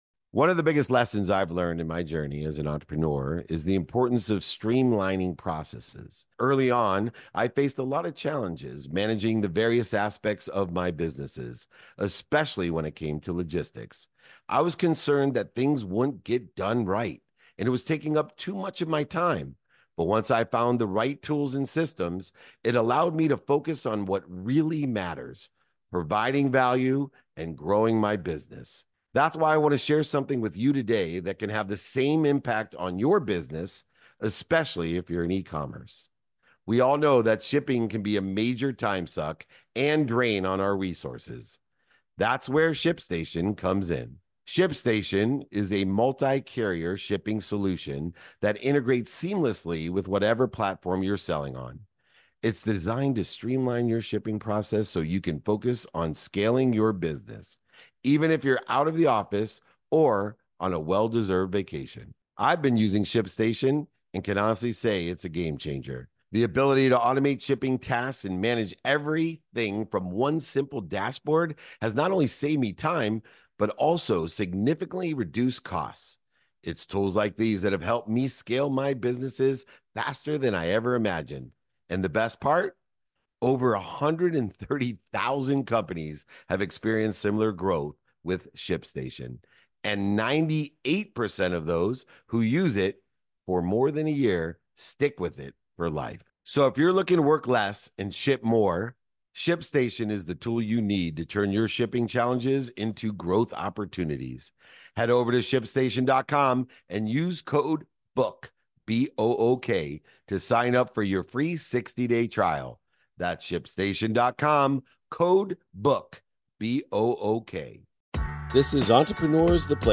In today's episode, I am joined by Tom Bilyeu, co-founder of Quest Nutrition and host of Impact Theory. We discuss how AI is revolutionizing the gaming industry and the broader implications of technological advancements.